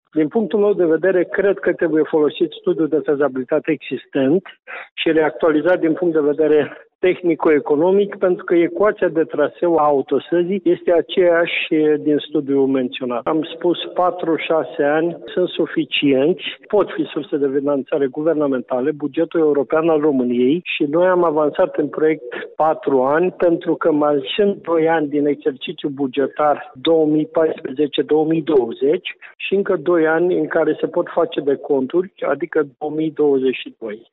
Deputatul PMP de Iaşi, Petru Movilă, a vorbit şi despre varianta de lucru care se bazează pe actualizarea studiului de fezabilitate existent  pentru finalizarea investiţiei într-un timp de maximum 6 ani: